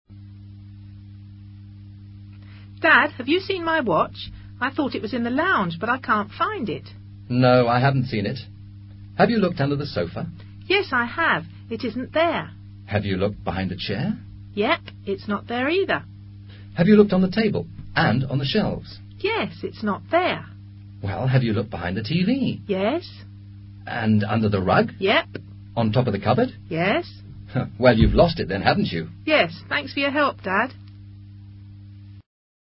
Una joven busca su reloj mientras es ayudada por su padre.
El presente diálogo es adecuado para ejercitar el uso del Present perfect y vocabulario de objetos, muebles y locaciones.